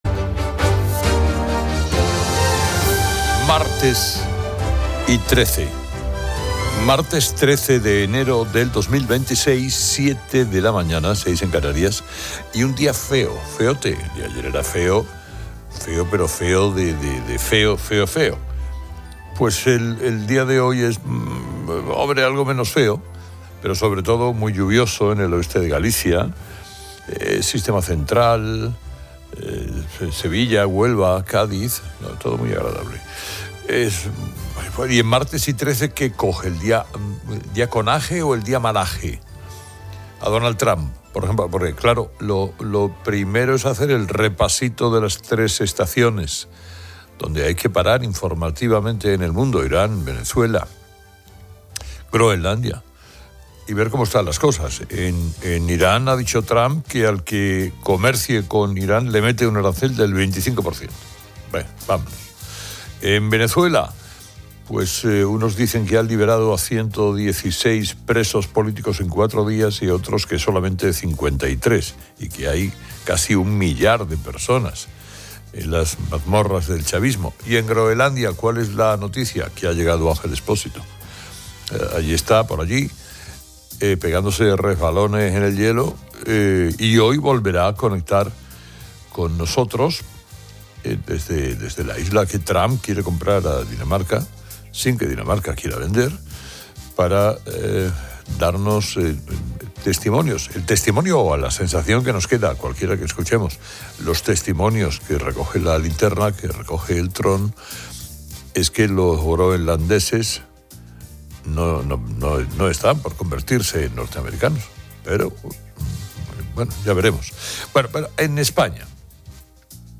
Desde Groenlandia, Ángel Expósito informa sobre el rechazo de los inuit a la posible compra de la isla por parte de Trump, defendiendo su cultura.